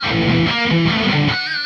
guitar01.wav